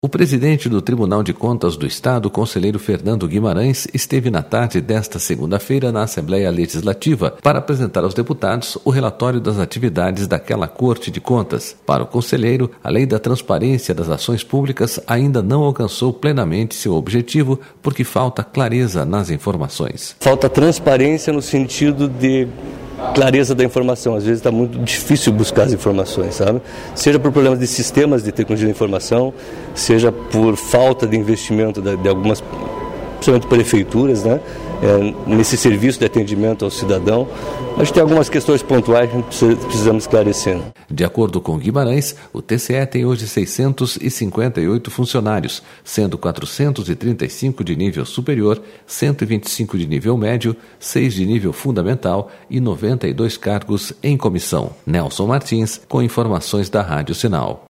SONORA GUIMARÃES